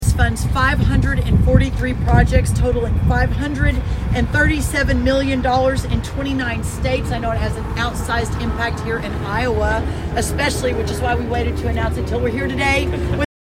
During her address, Secretary Rollins announced the USDA is releasing the funding for the higher blends infrastructure incentive program.